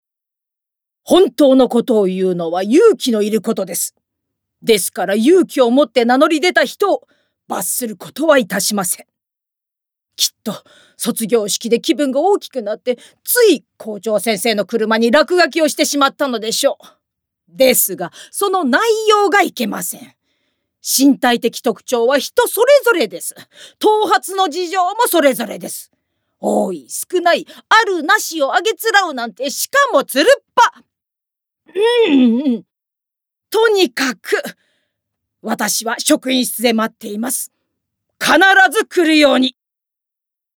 ボイスサンプル
セリフ５